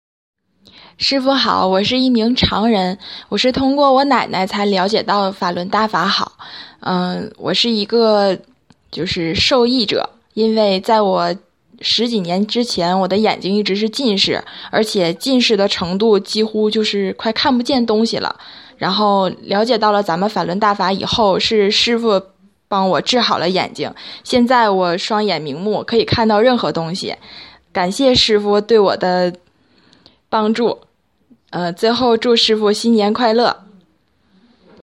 Một thương nhân ở thành phố Giai Mộc Tư: “Sau khi con liễu giải được Pháp Luân Đại Pháp, Sư phụ đã giúp con chữa khỏi đôi mắt, hiện nay mắt con đã sáng, có thể nhìn thấy tất cả mọi thứ”